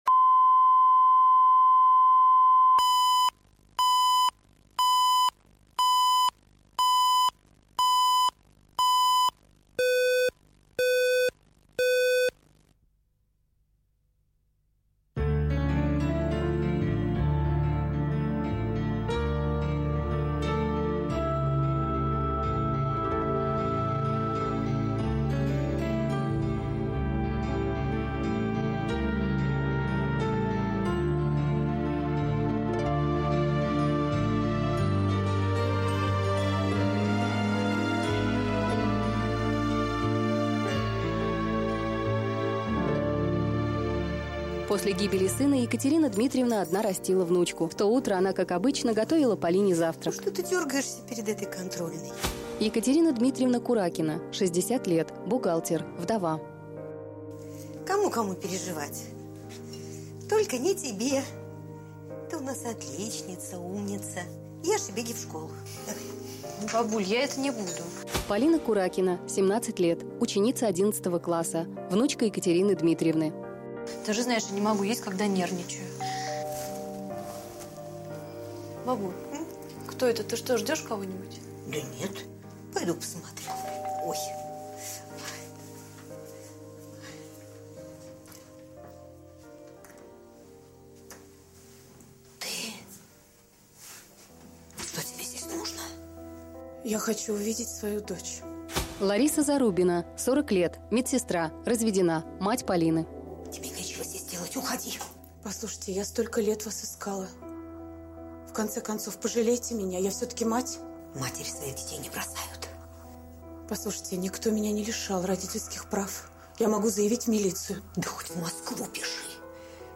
Аудиокнига Мое сокровище